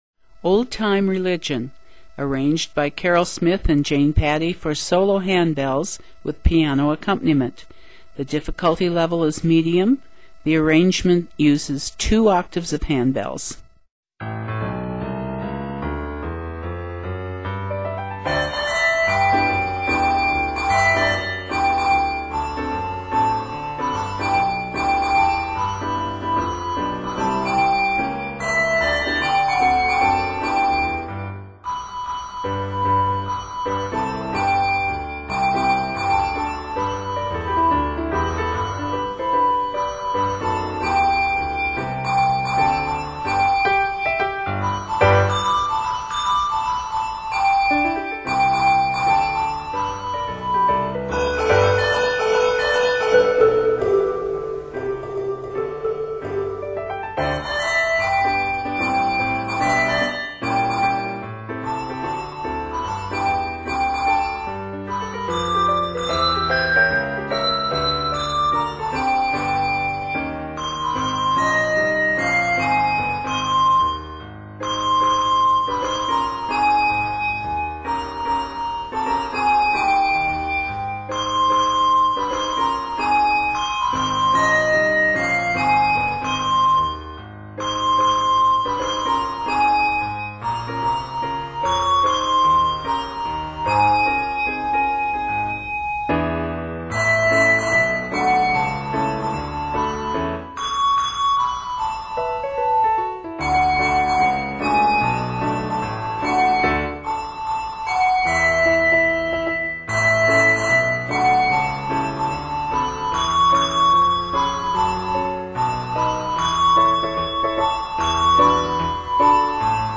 upbeat medley for the solo ringer
Traditional Spiritual Arranger
Solo Ringer
Technique: Mallet , RT (Ring Touch) , Sk (Shake)
Instrument: Piano